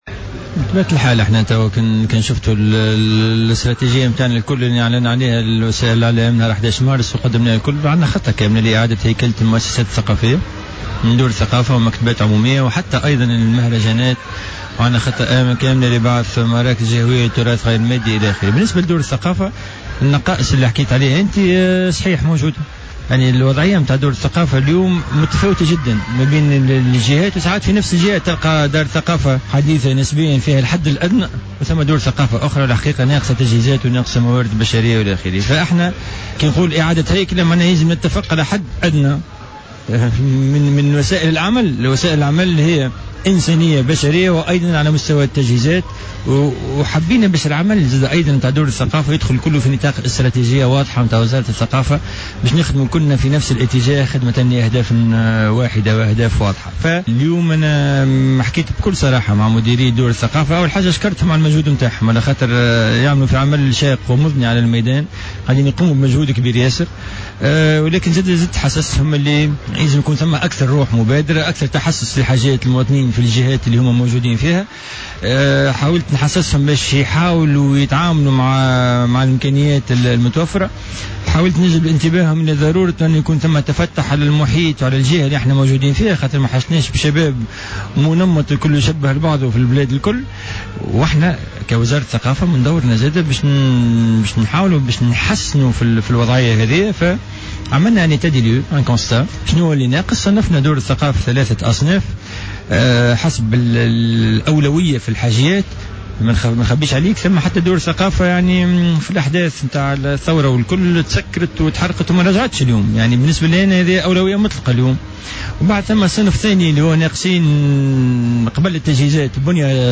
أكد وزير الثقافة،مراد الصقلي في مداخلة له في برنامج "بوليتيكا" وجود خطة كاملة لإعادة هيكلة المؤسسات الثقافية من دور ثقافة ومكتبات عمومية وحتى المهرجانات وإحداث مراكز جهوية للتراث غير المادي.